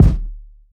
rtey_kik.wav